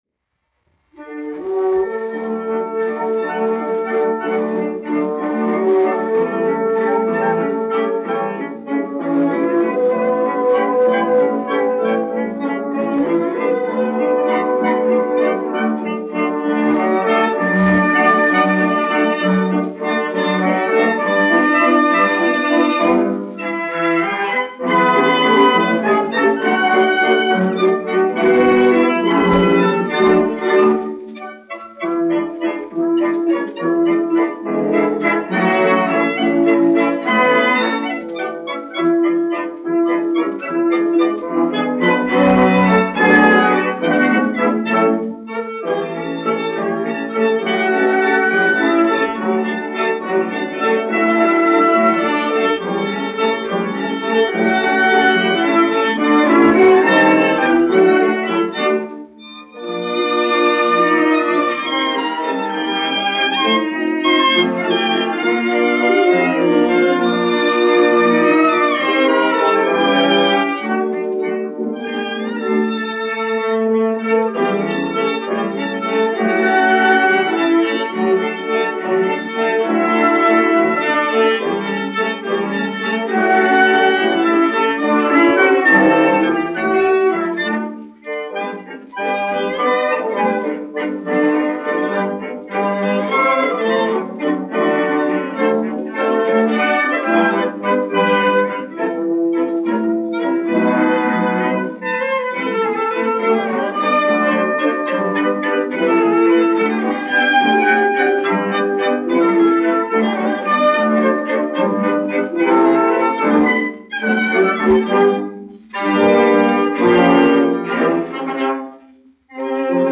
Rīgas Radiofona kamerorķestris, izpildītājs
1 skpl. : analogs, 78 apgr/min, mono ; 25 cm
Valši
Orķestra mūzika
Skaņuplate